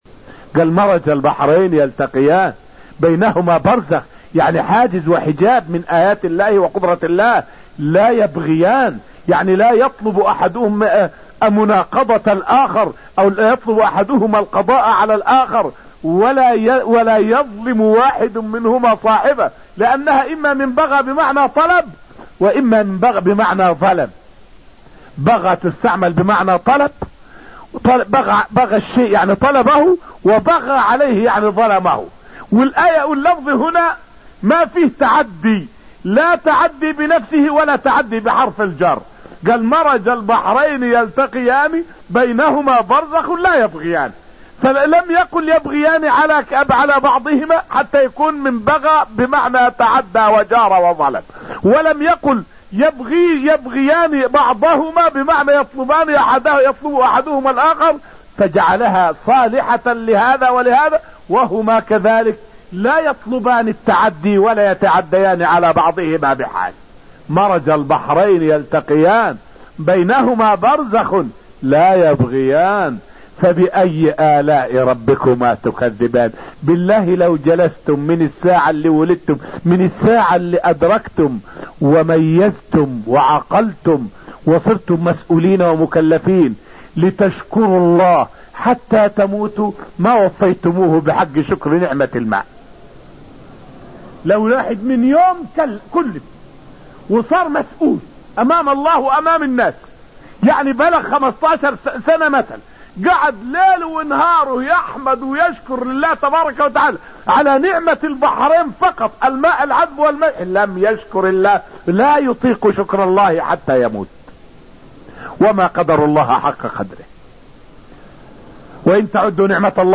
فائدة من الدرس السابع من دروس تفسير سورة الرحمن والتي ألقيت في المسجد النبوي الشريف حول المقصود بالبرزخ في قوله تعالى: {بَيْنَهُمَا بَرْزَخٌ}.